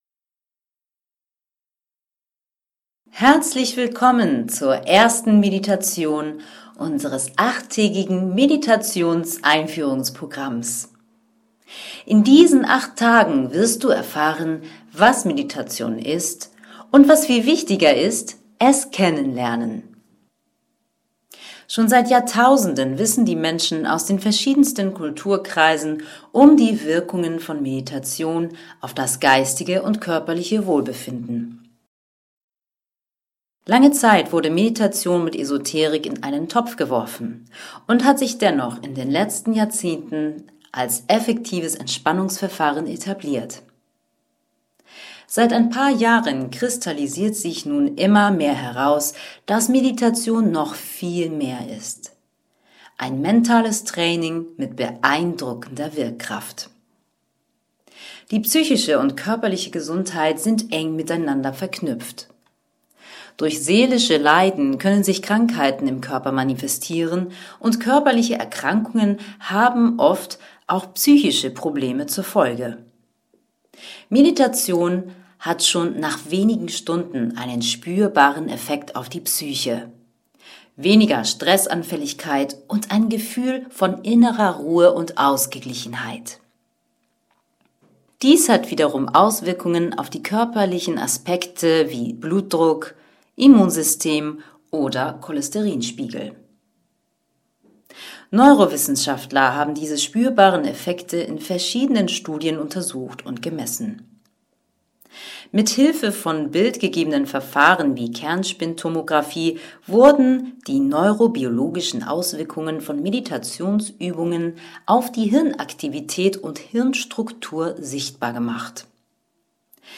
Einführung in die Meditation und Übung „Atemmeditation“ (Audio)
Meditation1_GeführterAtem.mp3